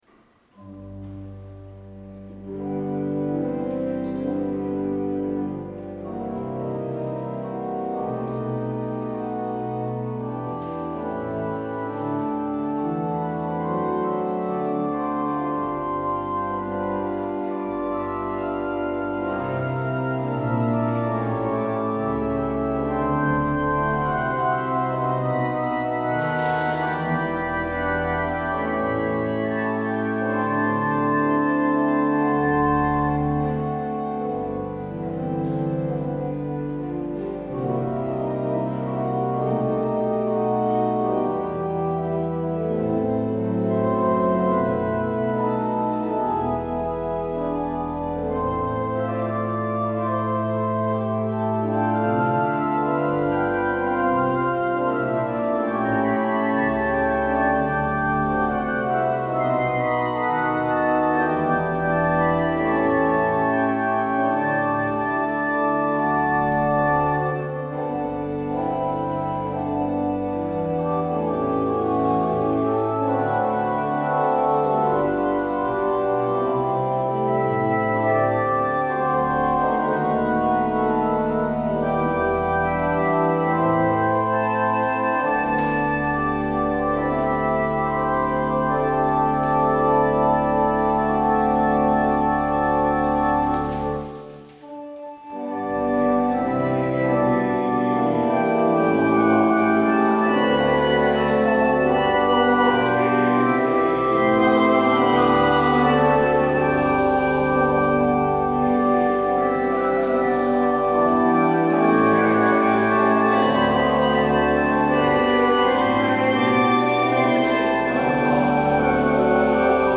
 Luister deze kerkdienst hier terug: Alle-Dag-Kerk 28 februari 2023 Alle-Dag-Kerk https
Als openingslied hoort u: Lied 975: 1, 2 en 3 (‘Jezus roept hier mensen samen’).